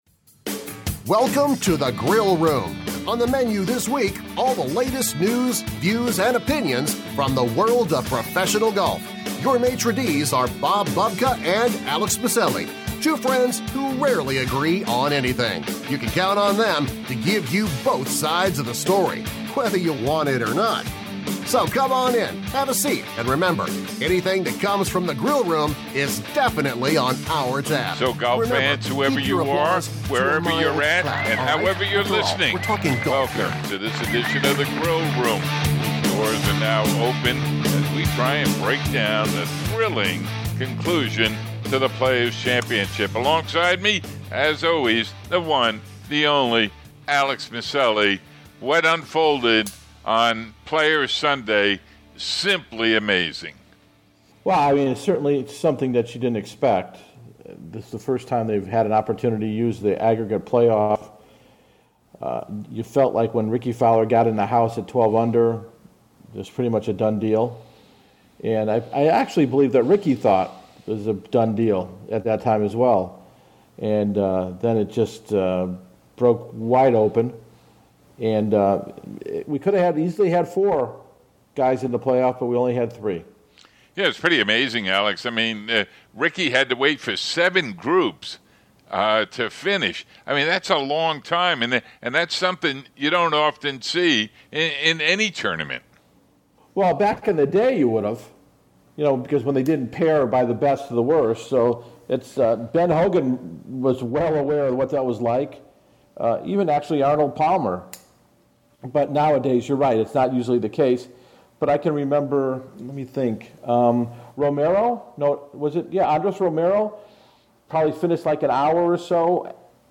Around the World of Golf checks in on the European Tour. News & Notes is followed by the Feature Interview, LPGA Commissioner Michael Whan.